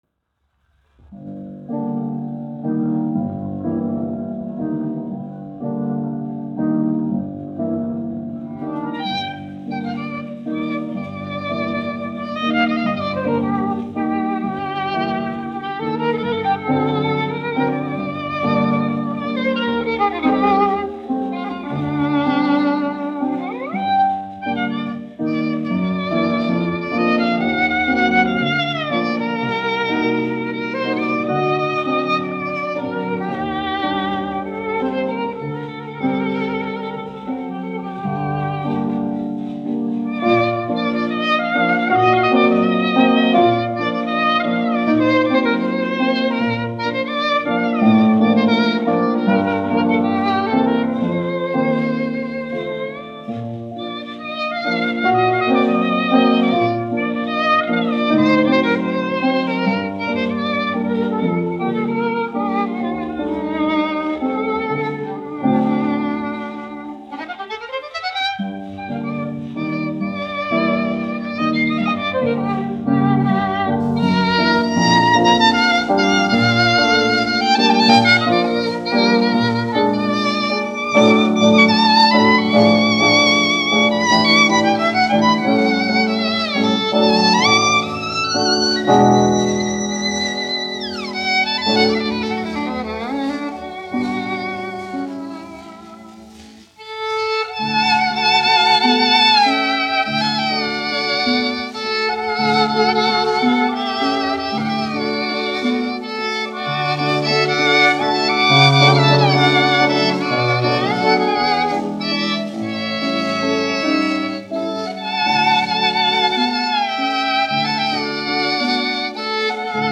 1 skpl. : analogs, 78 apgr/min, mono ; 25 cm
Vijoles un klavieru mūzika
Skaņuplate